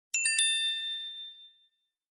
Download Notification sound effect for free.
Notification